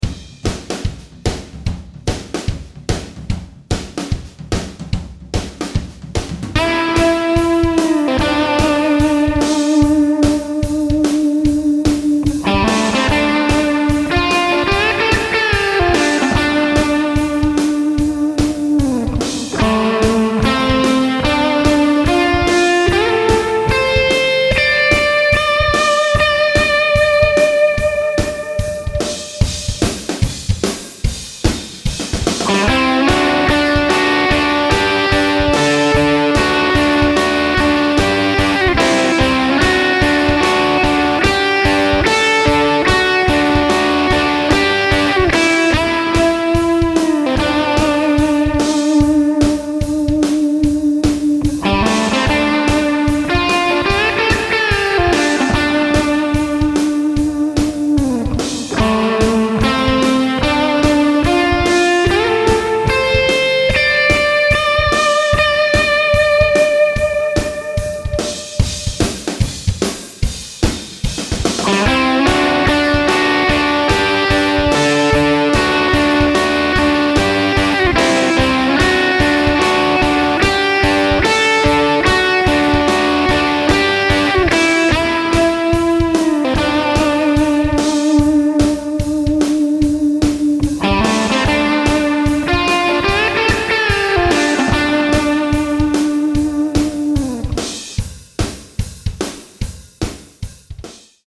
soundscape sound scape fx special fx